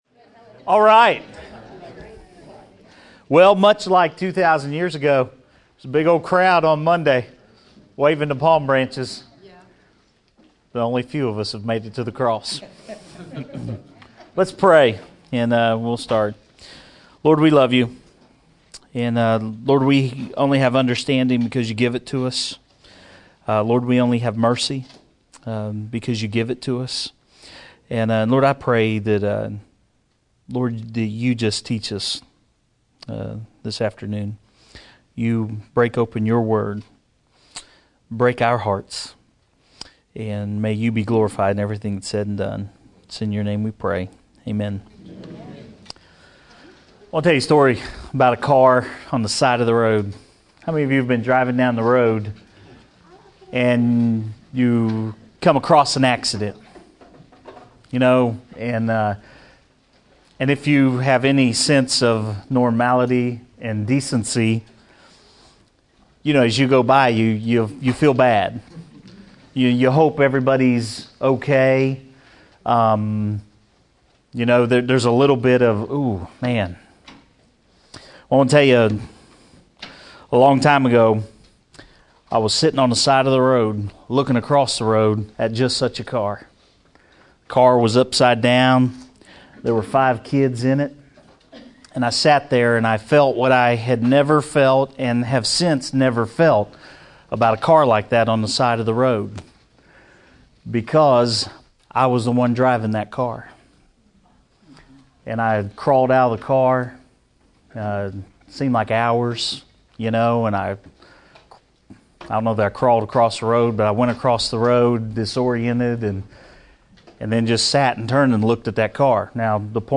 Noon Bible studies from Holy Week 2011.